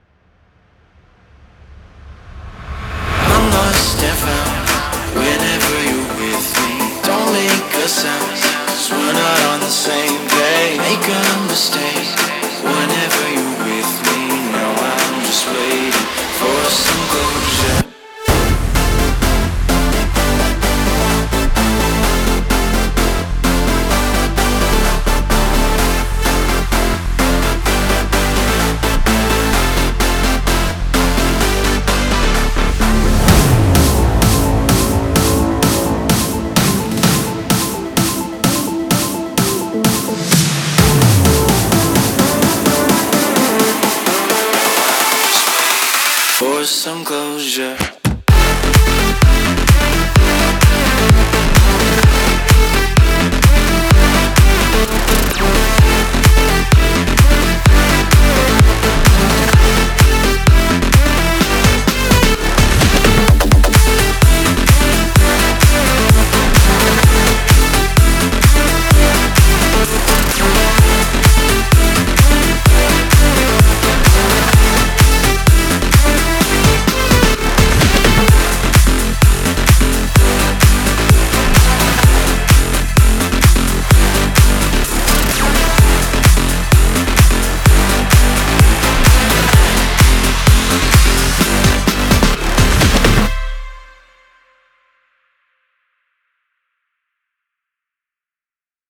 это мощная электронная композиция в жанре EDM